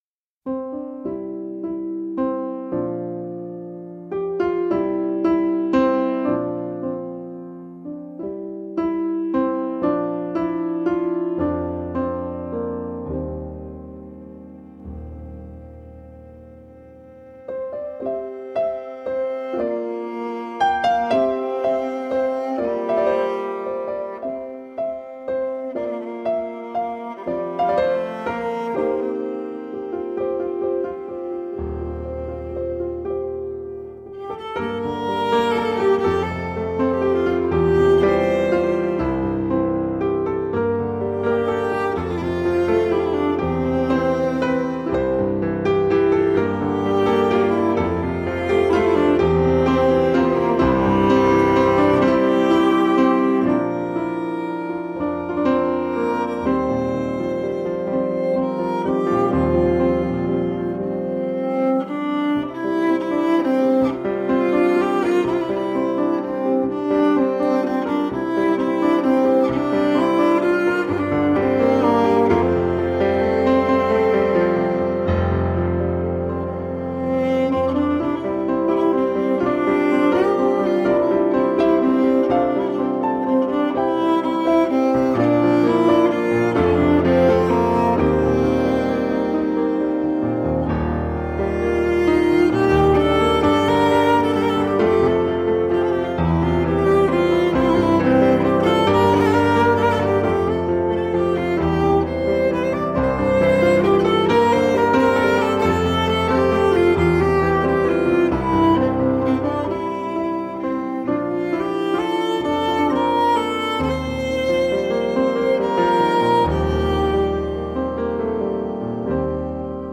Dreamy music
Tagged as: New Age, Ambient, Cello, Ethereal